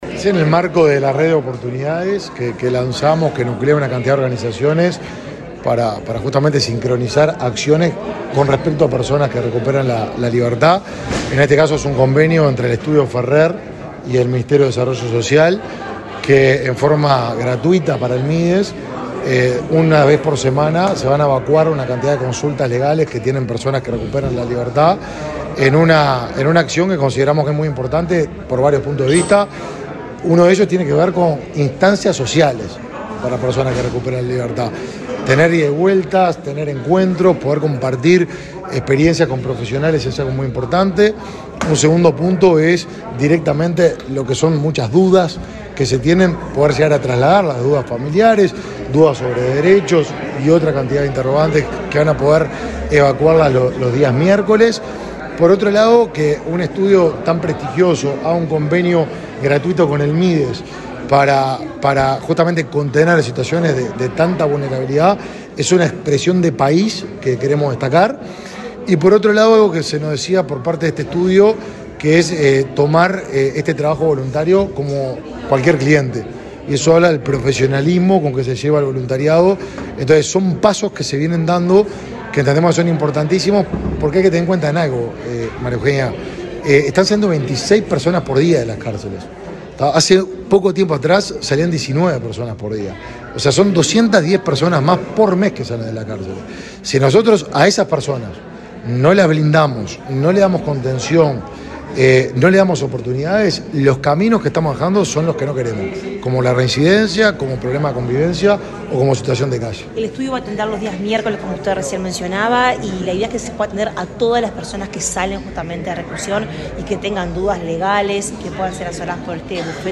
Declaraciones del ministro de Desarrollo Social, Martín Lema
Declaraciones del ministro de Desarrollo Social, Martín Lema 26/09/2023 Compartir Facebook X Copiar enlace WhatsApp LinkedIn Este martes 26, el ministro de Desarrollo Social, Martín Lema, participó del acto de instalación de un consultorio jurídico gratuito para personas que recuperan su libertad. Luego, dialogó con la prensa.